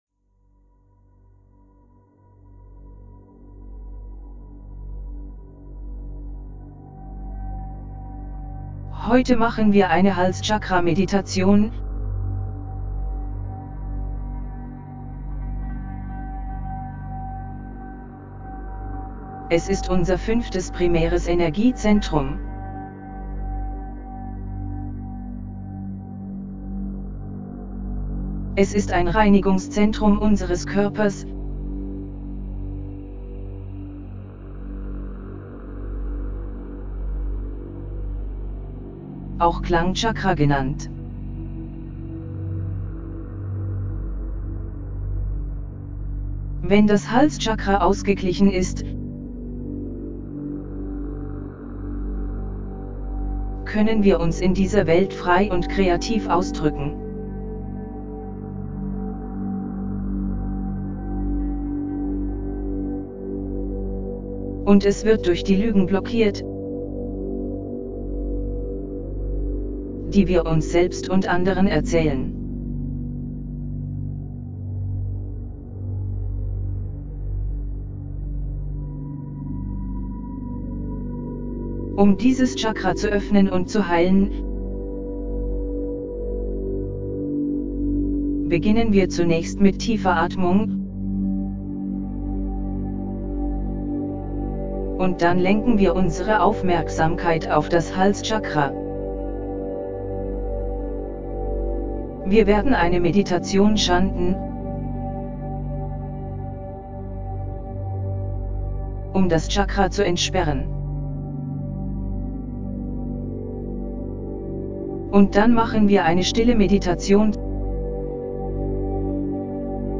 5ThroatChakraHealingGuidedMeditationDE.mp3